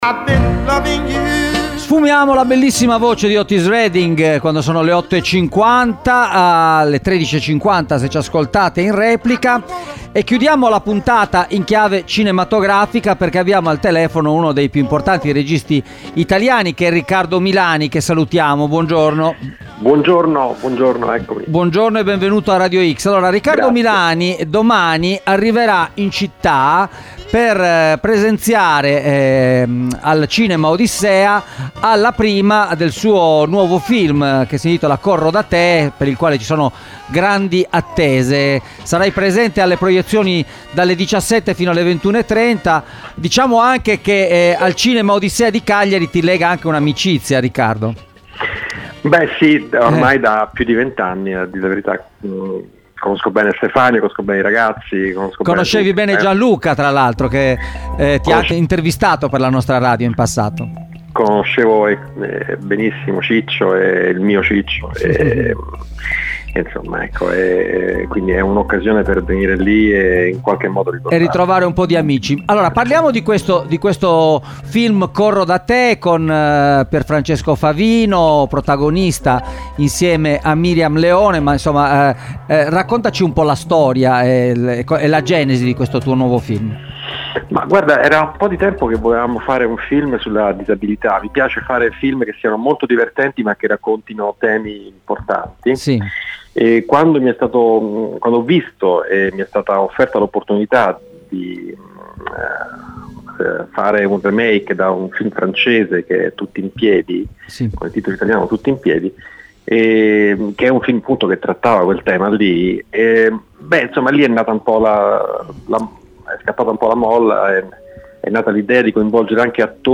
“Corro da te” al Cinema Odissea – Intervista con il regista Riccardo Milani